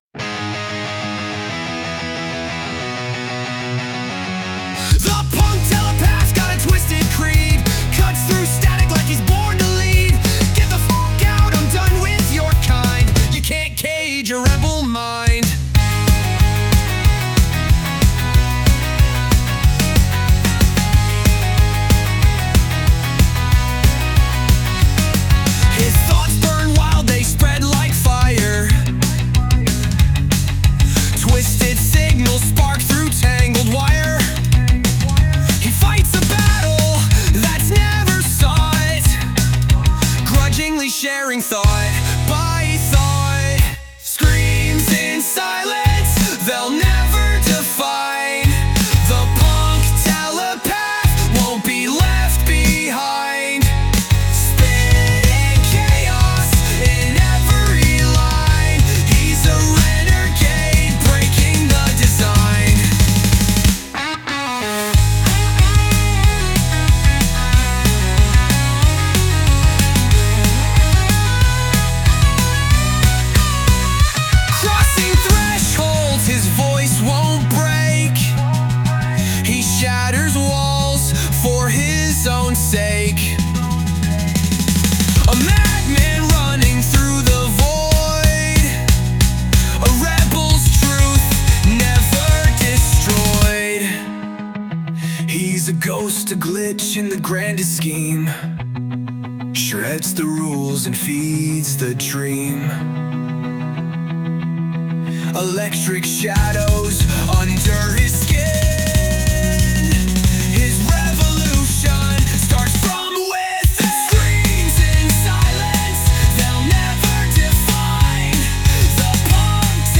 English, Punk, Metal | 18.04.2025 12:51